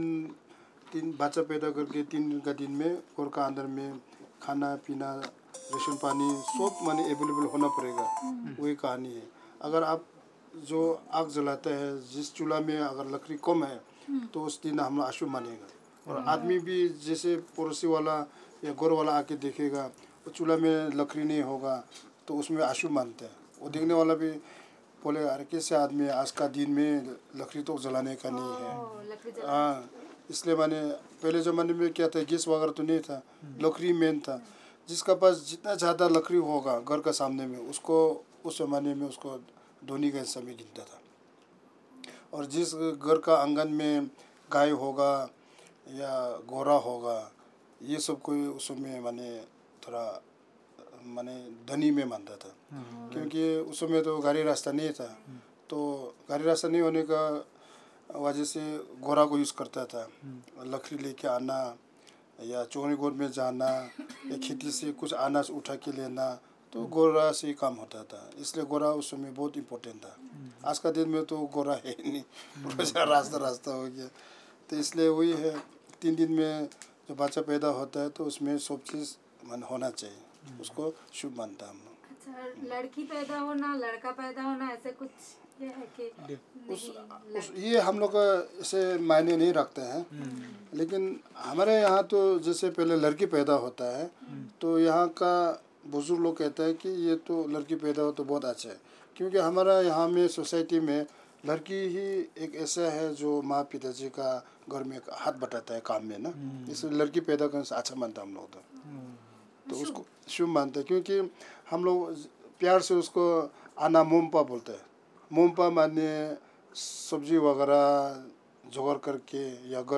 170618_Story narration.WAV (26.99 MB)